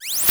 open_004.ogg